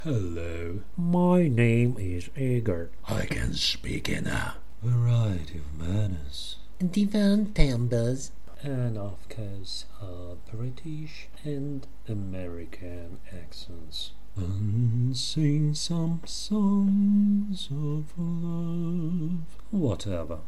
Англ.голос1.mp3